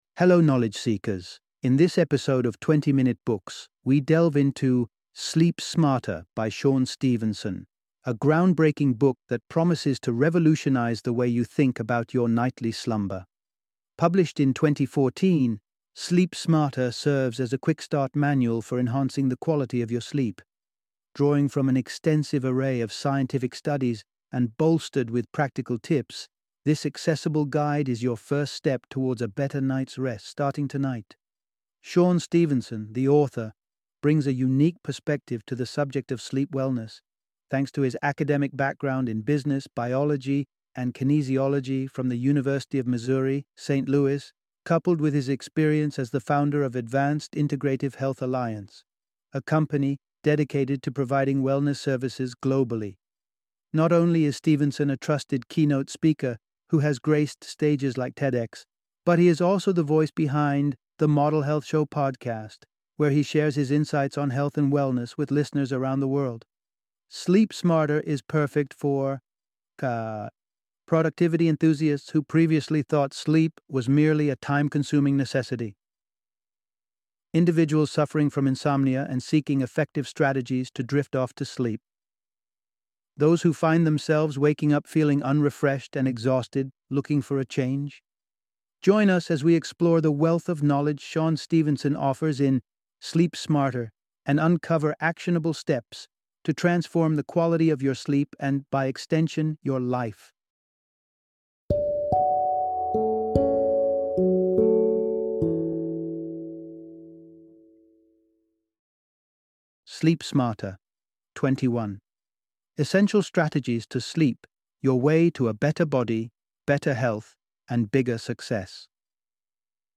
Sleep Smarter - Audiobook Summary